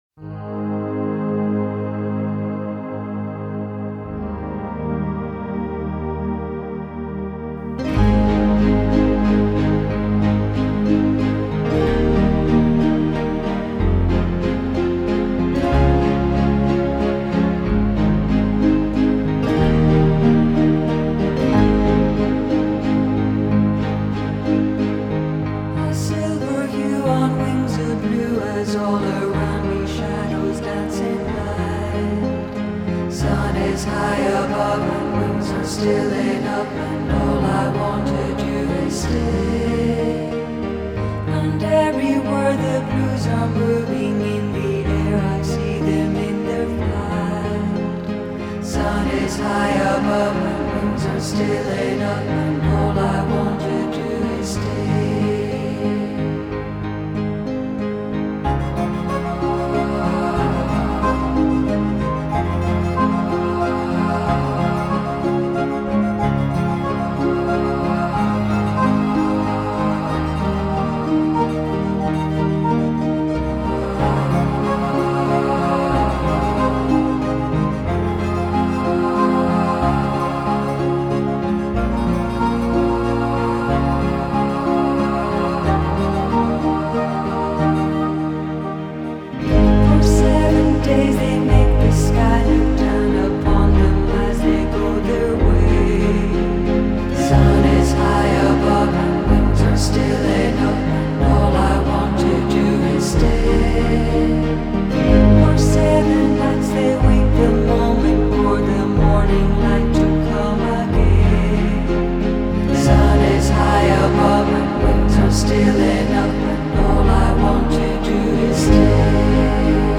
Genre: New Age, Ambient, Relax.